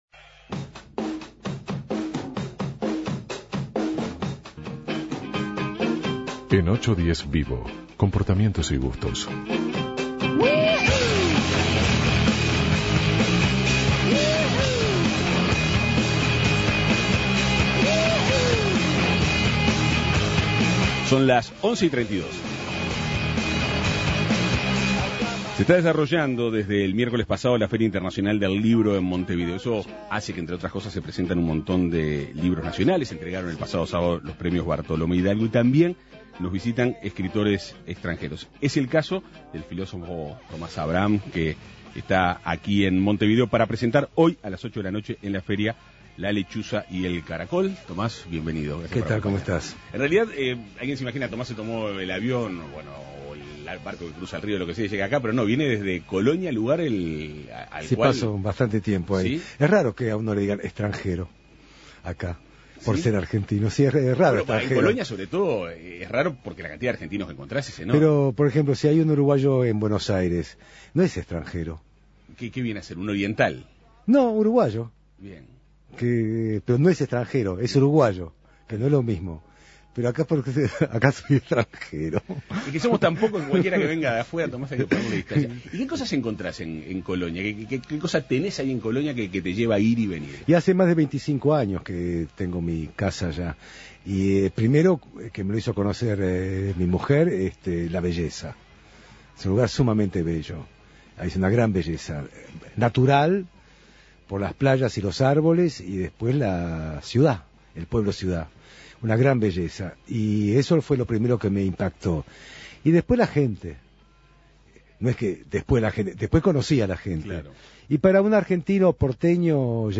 En diálogo con 810VIVO Avances, tendencia y actualidad, el filósofo argentino Thomas Abraham habló sobre su libro "La lechuza y el caracol", que se presentará hoy 8 de octubre en la 35° Feria Internacional del libro de Montevideo.